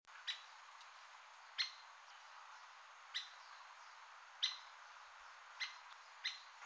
74-1小啄木1蘭潭2013.WAV
小啄木 Dendrocopos canicapillus kaleensis
嘉義市 西區 蘭潭
錄音環境 雜木林
行為描述 鳴叫